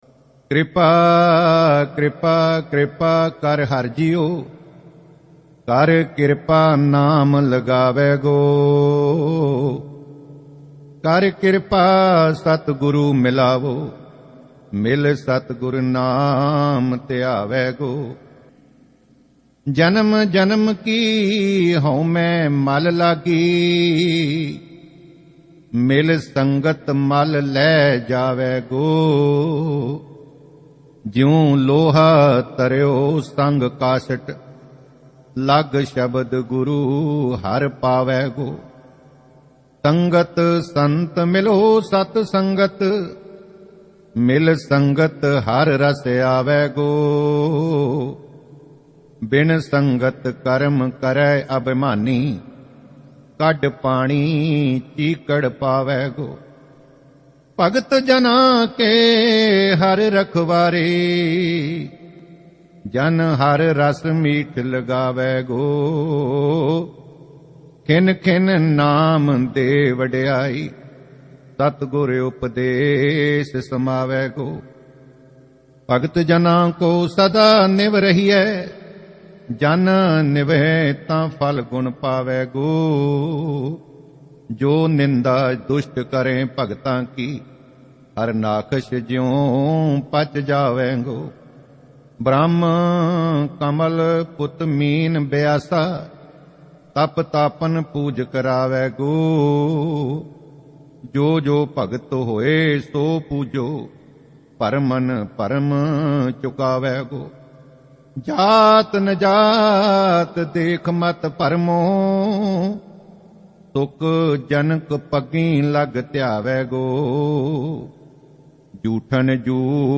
Sehaj Paath Ang-1309 add
SGGS Sehaj Paath